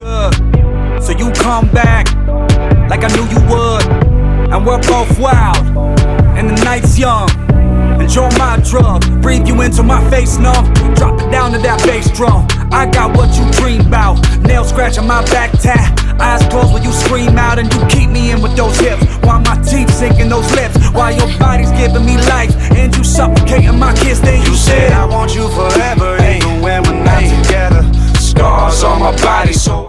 • Hip-Hop/Rap
mid-tempo ballad